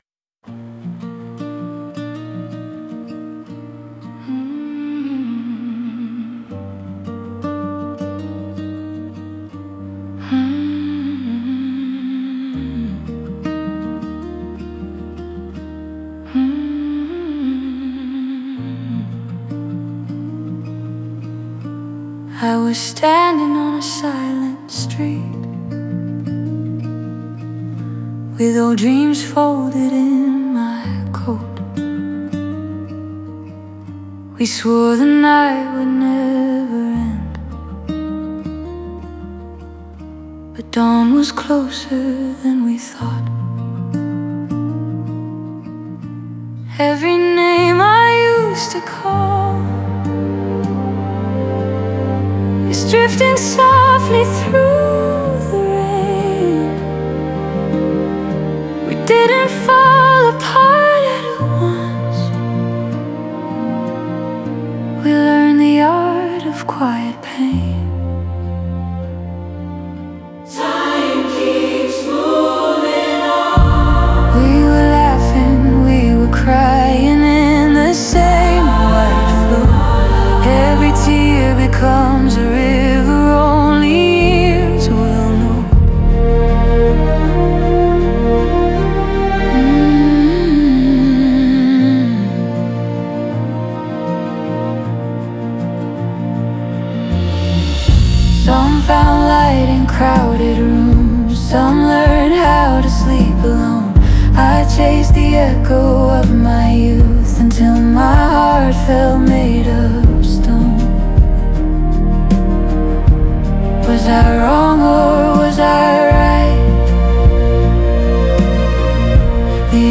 中盤からはコーラスが重なり始め、個人の声が他者の声と溶け合うことで、ひとりではないという感覚が生まれていく。